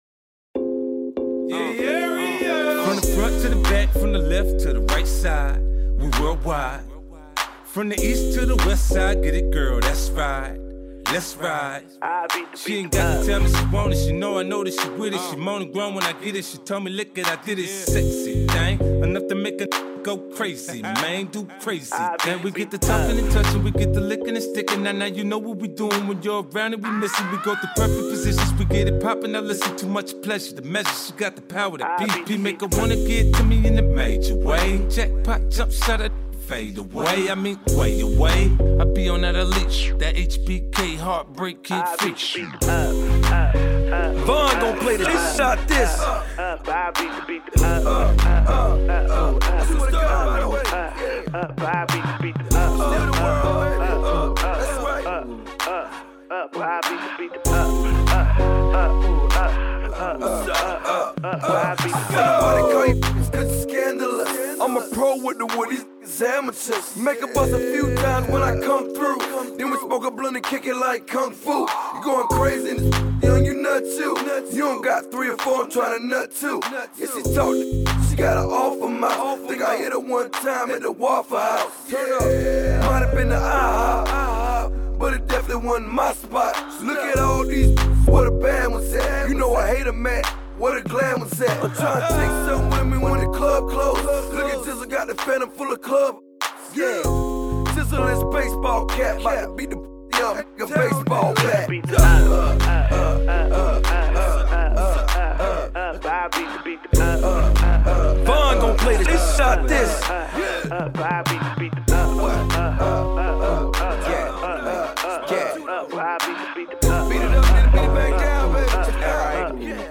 A remix of this track